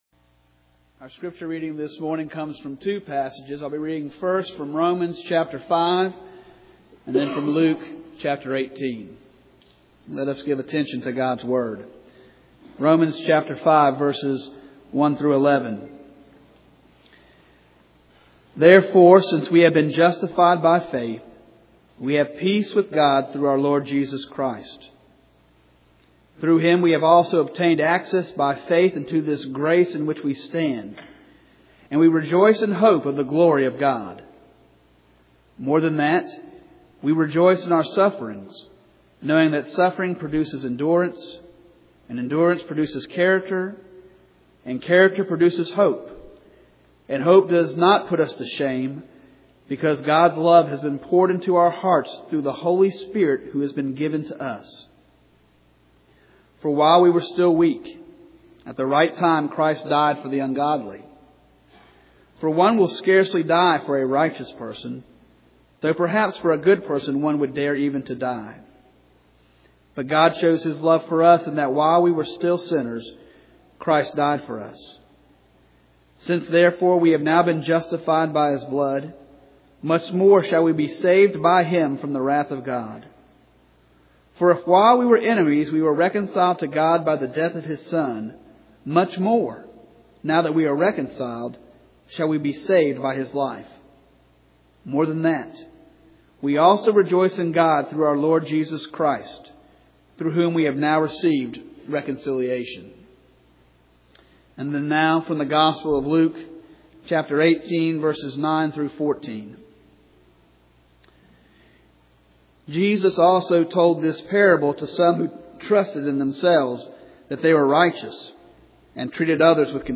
The Golden Chain Passage: Romans 5:1-11, Luke 18:9-14 Service Type: Sunday Morning « The Golden Chain